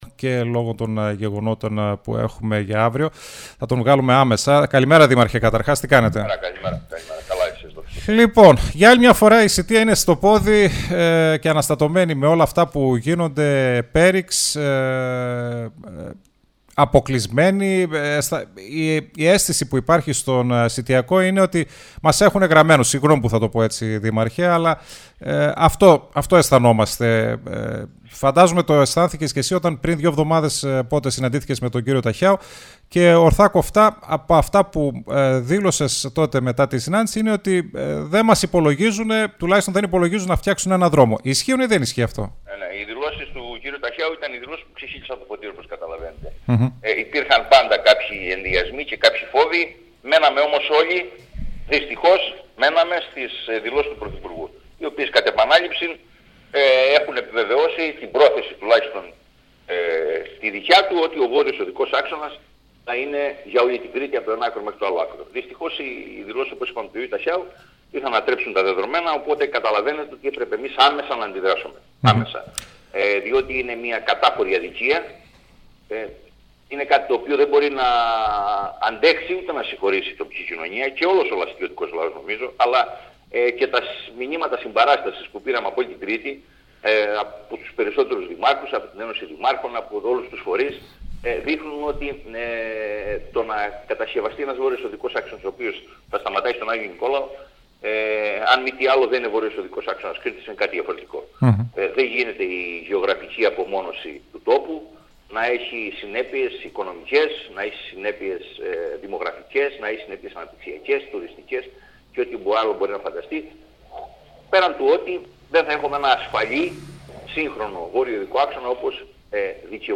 Ο Δήμαρχος Σητείας στο STYLE 100: Στο χέρι του πρωθυπουργού είναι να φτάσει ο ΒΟΑΚ στη Σητεία (ΗΧΗΤΙΚΟ)